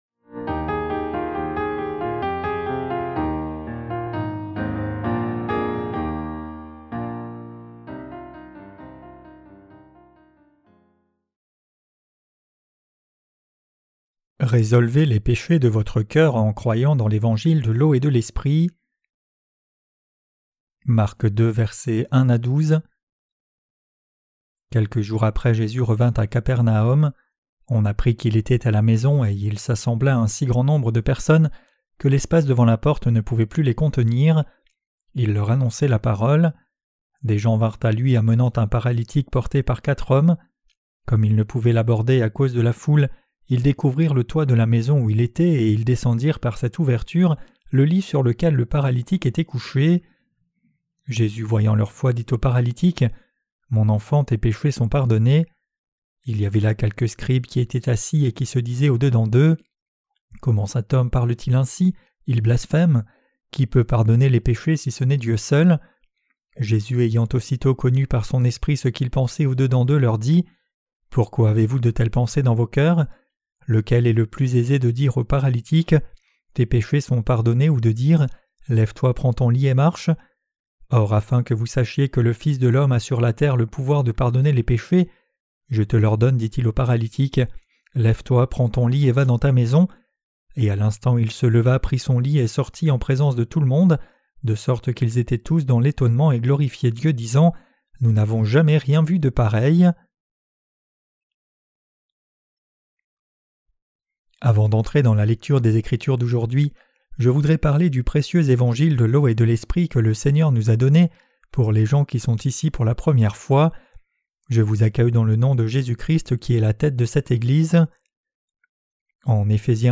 Sermons sur l’Evangile de Marc (Ⅰ) - QUE DEVRIONS-NOUS NOUS EFFORCER DE CROIRE ET PRÊCHER? 5.